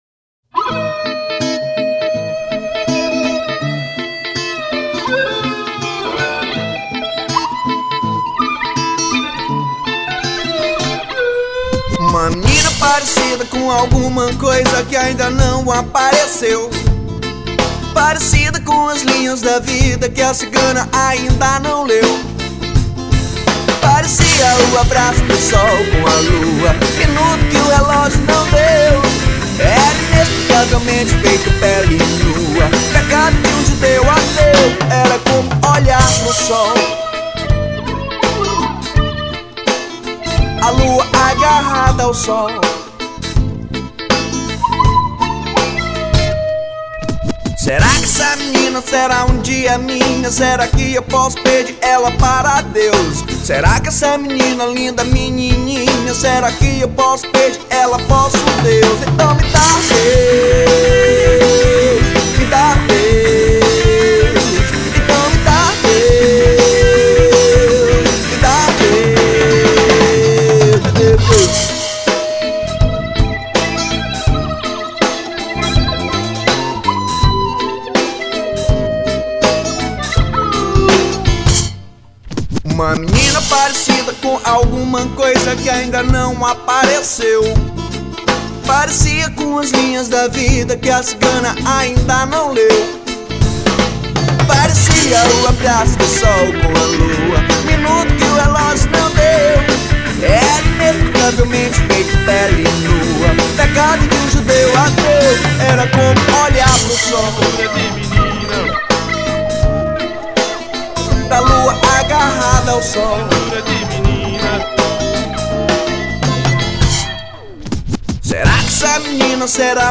1977   03:08:00   Faixa:     Rock Nacional